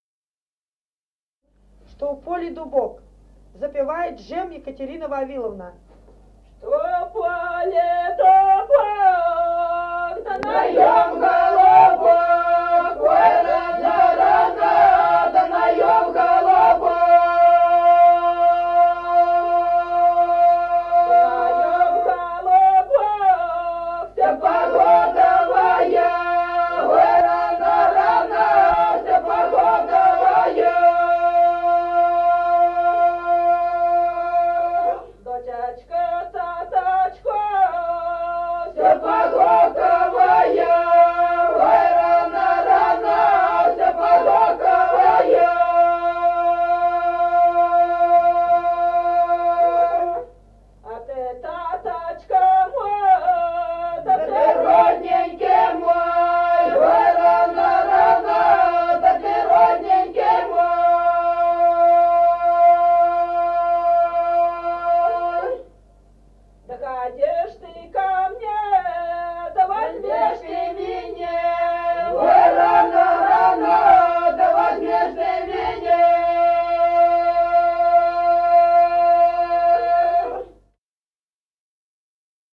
Народные песни Стародубского района «Что в поле дубок», свадебная, «на придане».